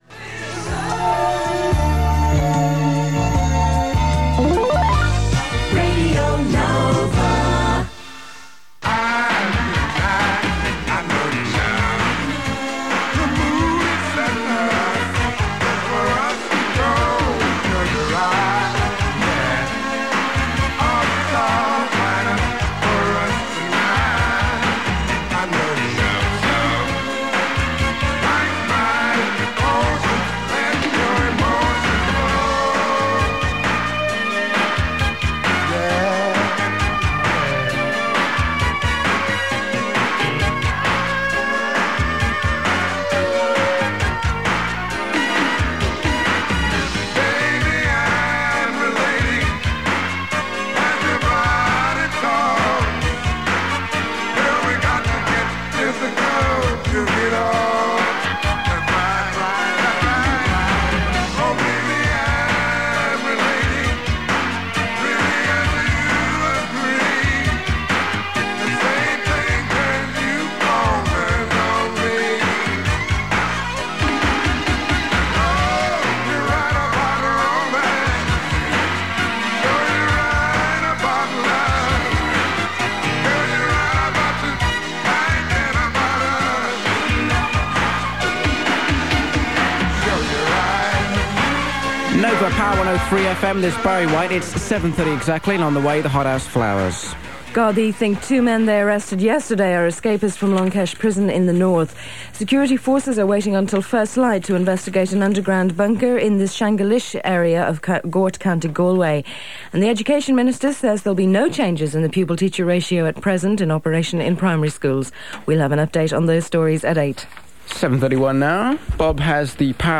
It is not clear if it was made from 99.9 or 103 FM.
The familiar high-level adverts are heard, including for brands, and the classic Nova jingles are played, creating something of an identity problem as they differ from the station’s name.